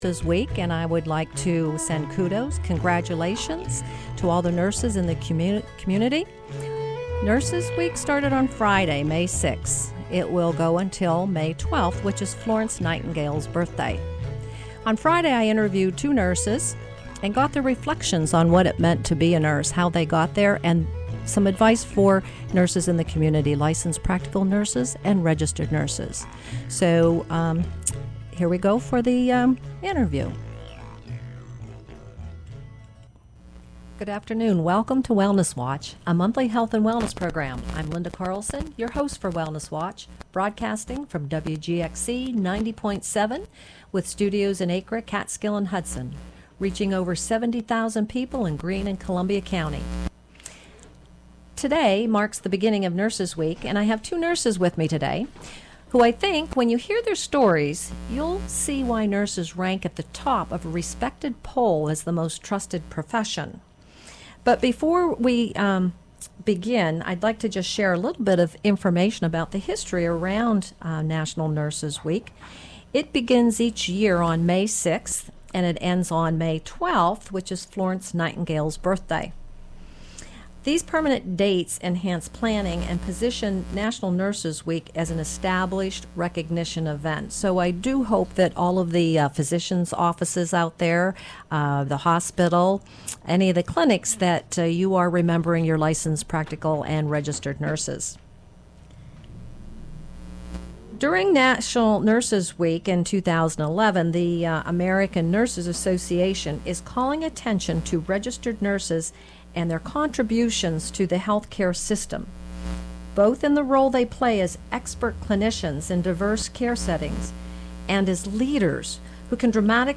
Interviews with two local nurses.